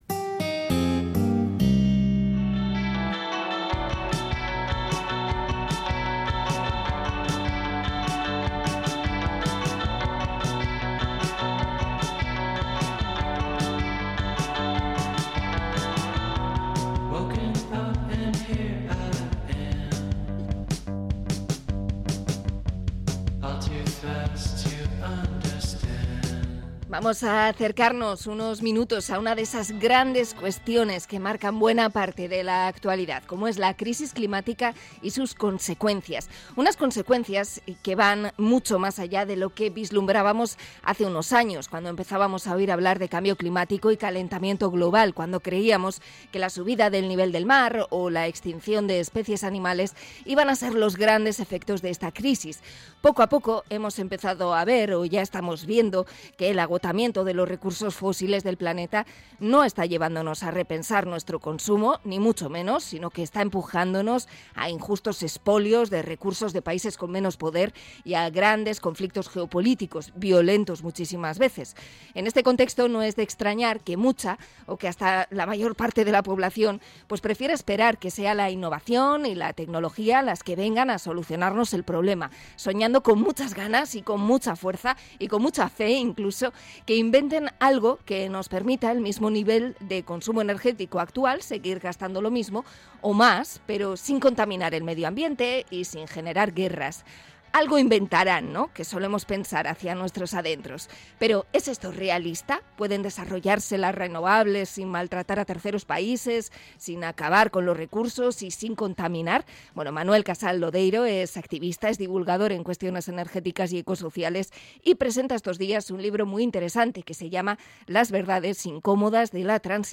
Entrevista a divulgador sobre la transición energética